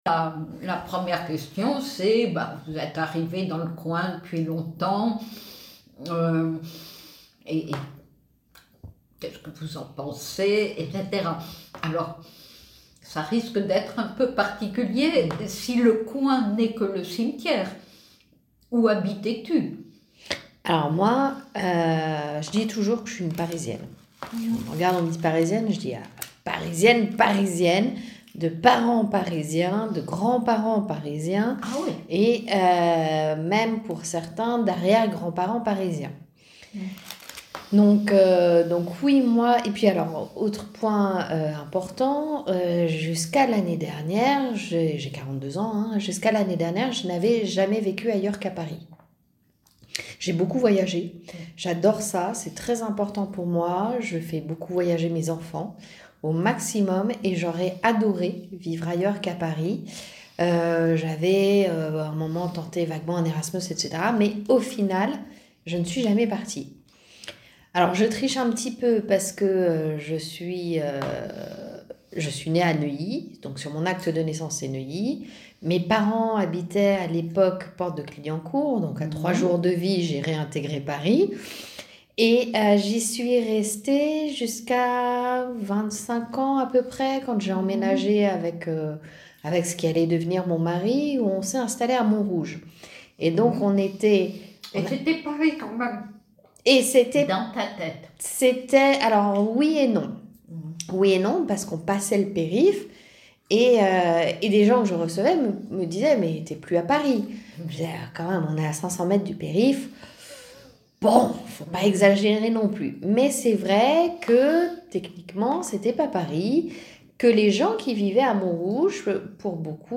DISCOURS SUR LA VILLE. CORPUS DE FRAN�AIS PARLE PARISIEN DES ANNEES 2000 (CFPP2000) - Paris3-Sorbonne nouvelle